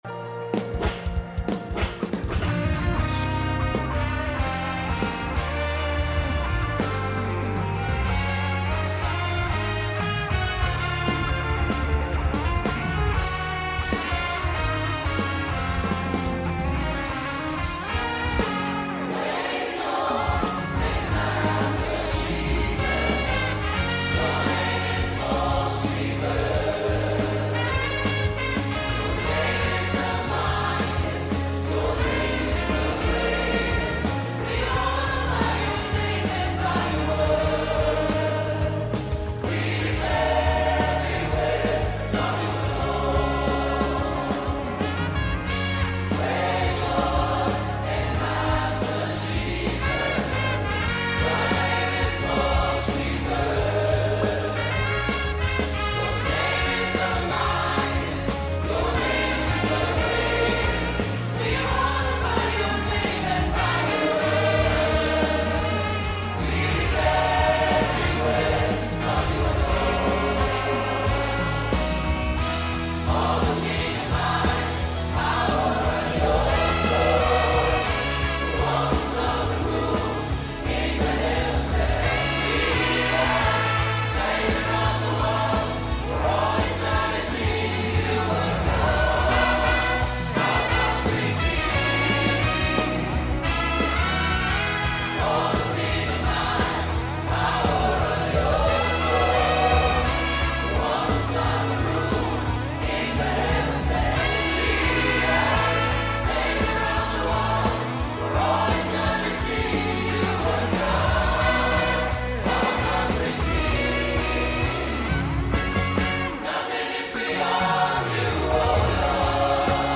MORE MEDLEYS